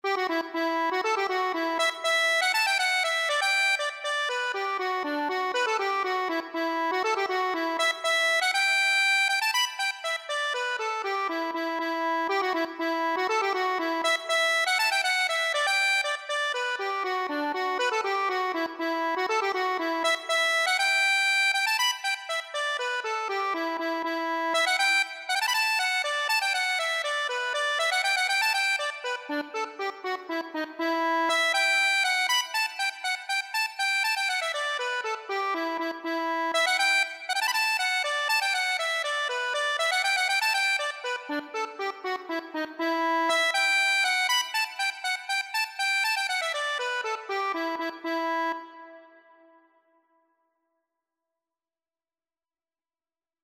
Free Sheet music for Accordion
Traditional Music of unknown author.
E minor (Sounding Pitch) (View more E minor Music for Accordion )
6/8 (View more 6/8 Music)
D5-B6
Accordion  (View more Easy Accordion Music)
Traditional (View more Traditional Accordion Music)